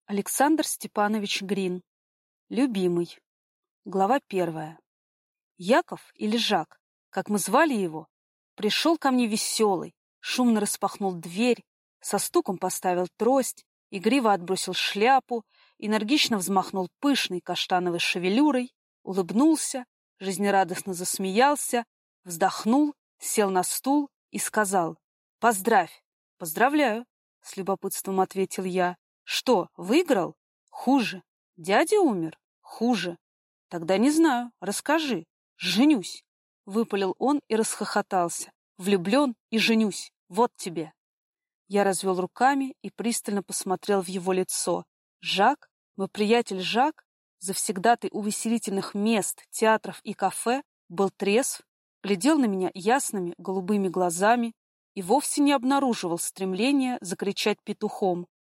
Aудиокнига Любимый